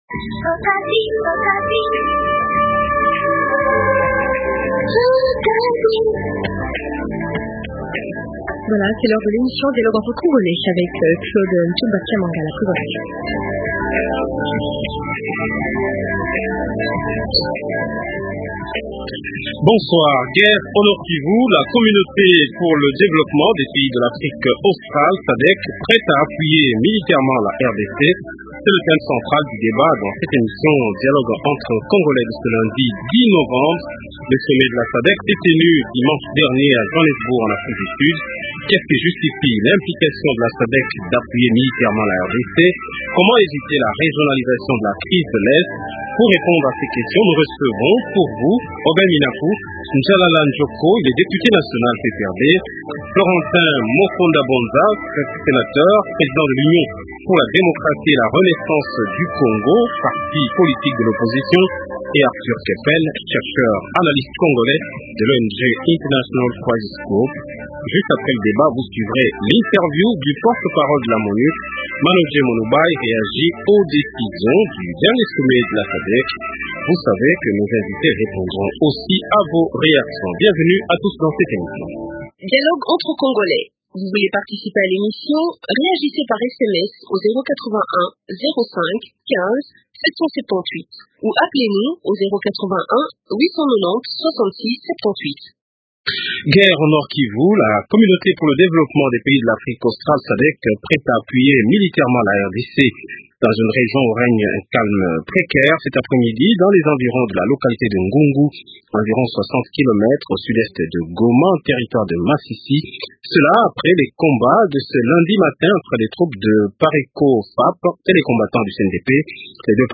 - Qu’est ce qui justifie l’implication de la SADEC ? - Comment éviter la régionalisation de la crise de l’Est ? Invités - Aubin Minaku Ndjalalandjoko, député national Pprd